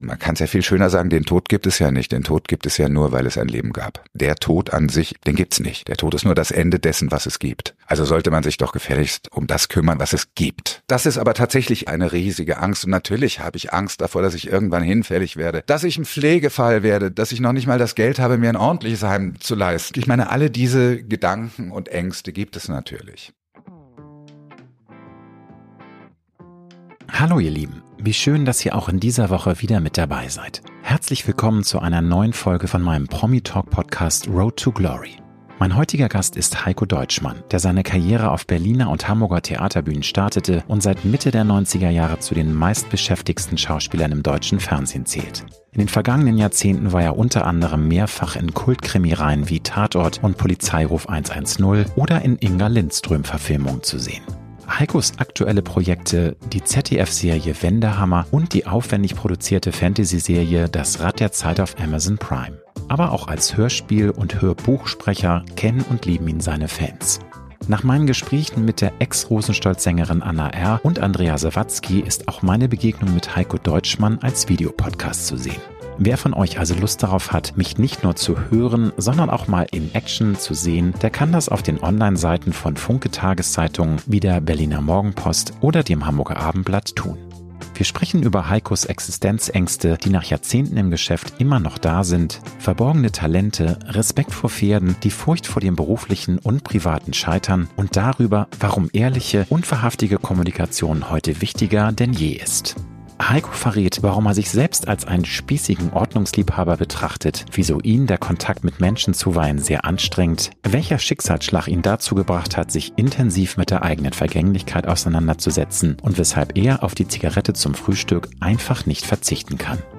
Mein heutiger Gast ist Heikko Deutschmann, der seine Karriere auf Berliner und Hamburger Theaterbühnen startete und seit Mitte der Neunzigerjahre zu den meistbeschäftigsten Schauspielern im deutschen Fernsehen zählt.